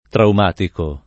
traumatico [ traum # tiko ]